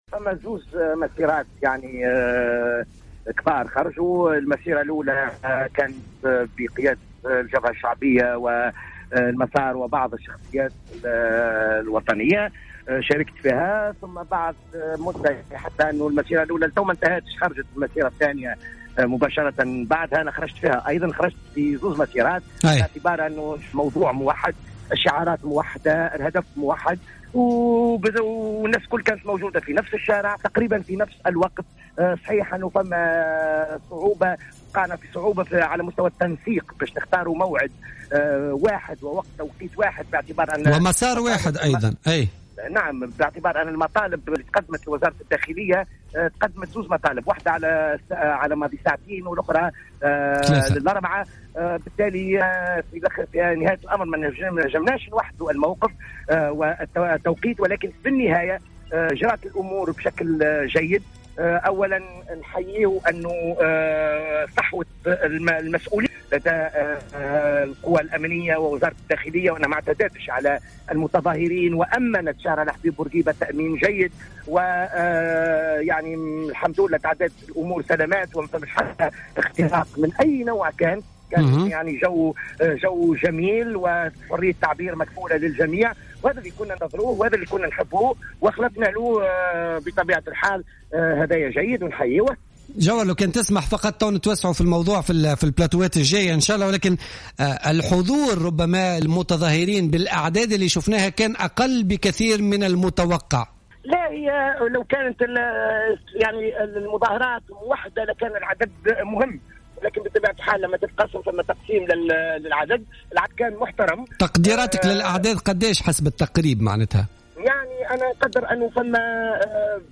قال جوهر بن مبارك، منسق عام شبكة دستورنا لدى تدخله على موجات إذاعة جوهرة خلال الحصة الخاصة بالمسيرة المناهضة لقانون المصالحة الاقتصادية اليوم السبت في العاصمة، قال إن مسيرتين تم تنظيمهما وقد دارتا في ظروف طيبة وسط تأمين من قوات الأمن التي ساهمت في إنجاحهما.